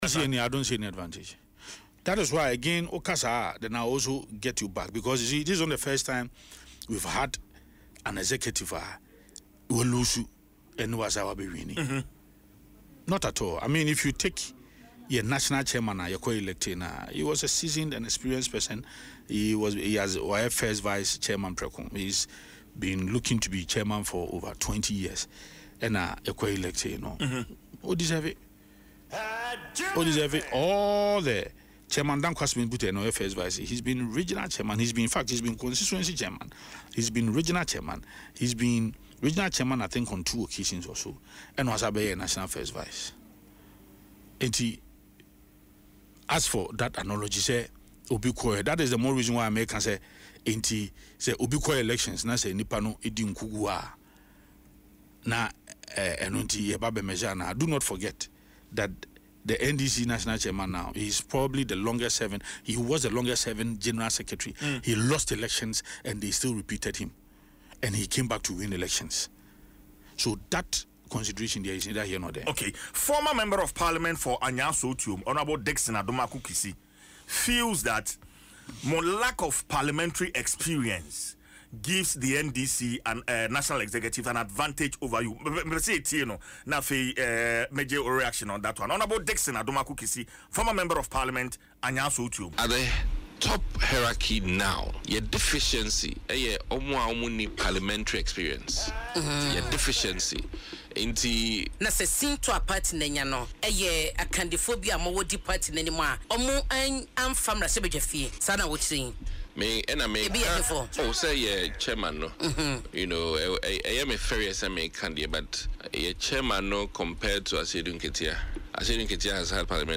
described the remarks as misplaced during an interview on Adom FM’s Dwaso Nsem.